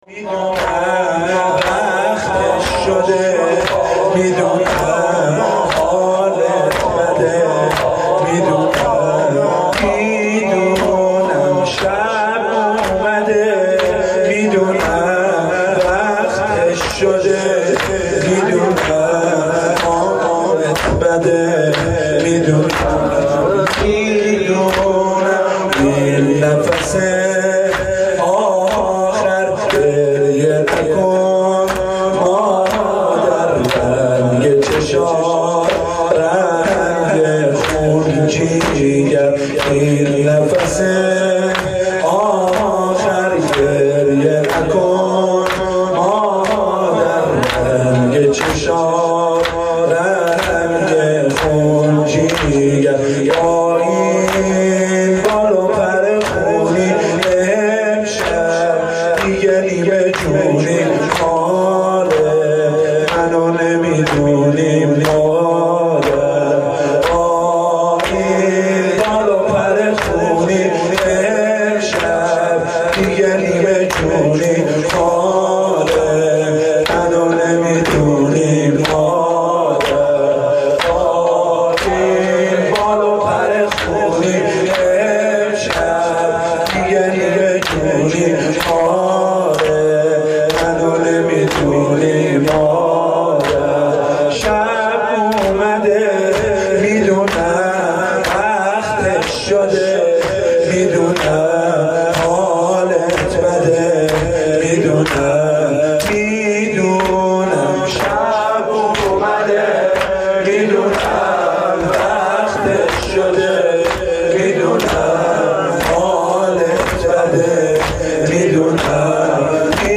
هیئت رایت الهدی کمالشهر
مداحی فاطمیه